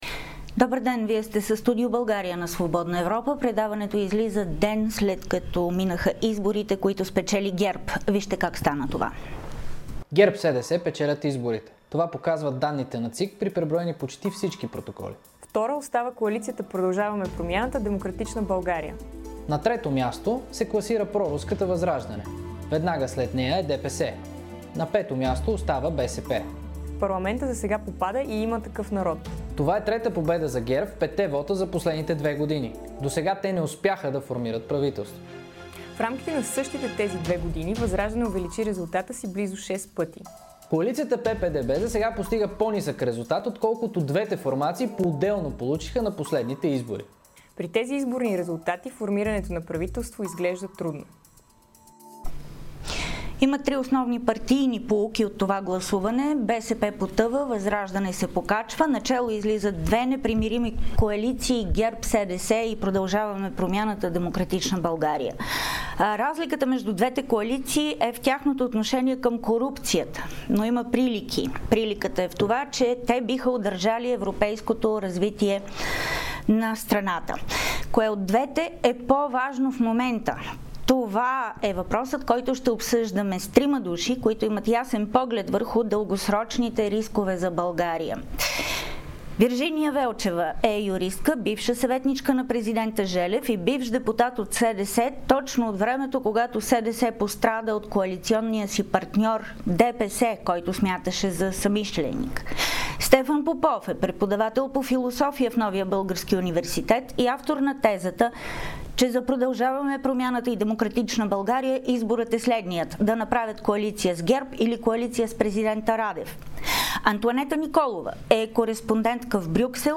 Разговор с трима анализатори